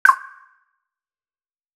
01Signal_Mashina.wav